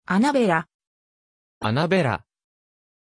Pronuncia di Annabella
pronunciation-annabella-ja.mp3